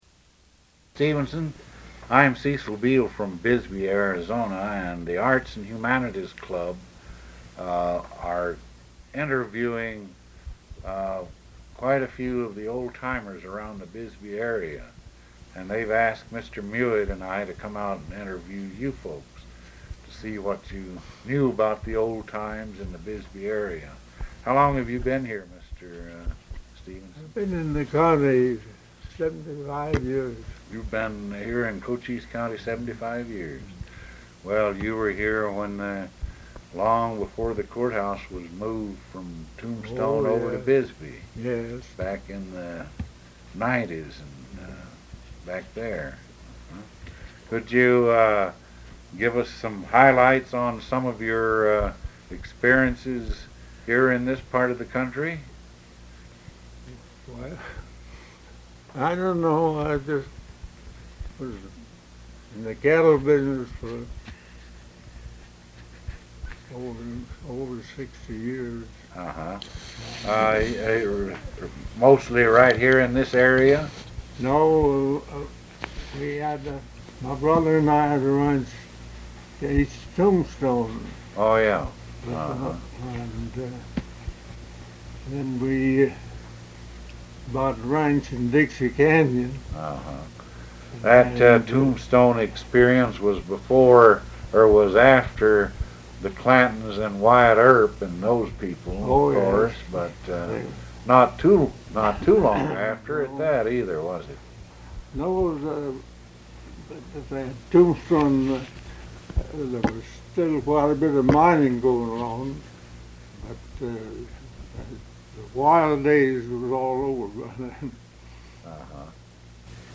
Bisbee’s storied past is recorded, reflected, and retold in the Museum’s oral history collection.